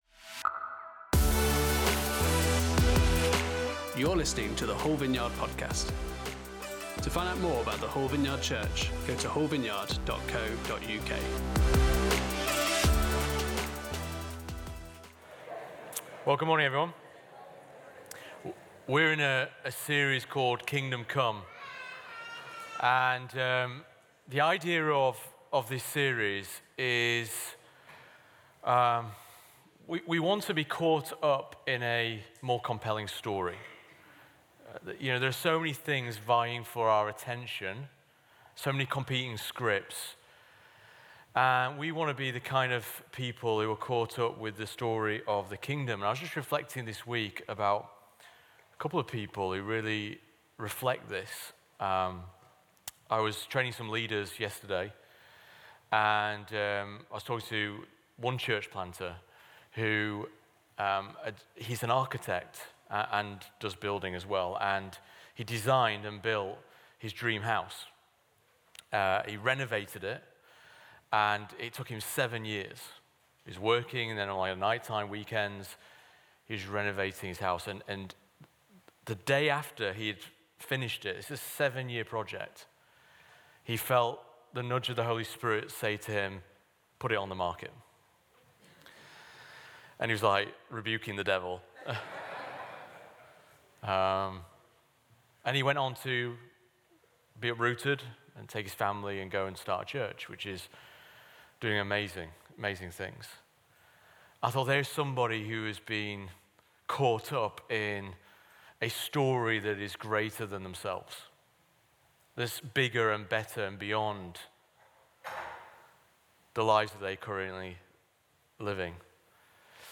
Series: Kingdom Come Service Type: Sunday Service